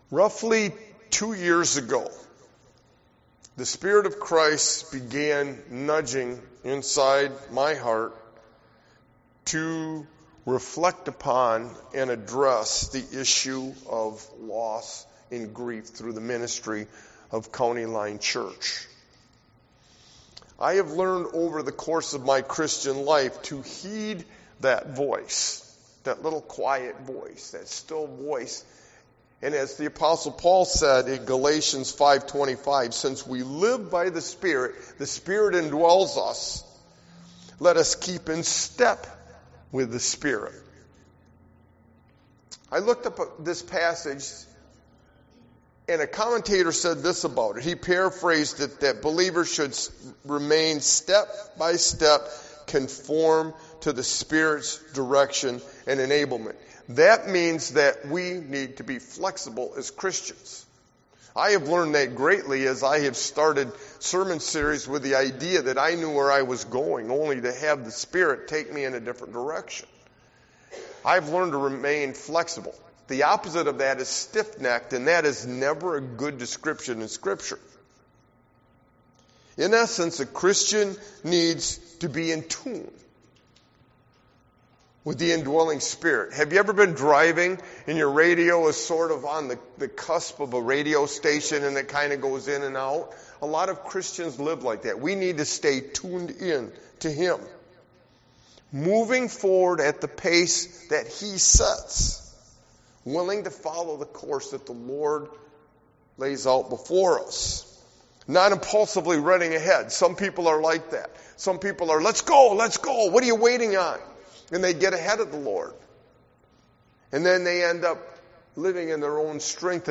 Sermon Walking With Jesus through Difficulty and Loss 2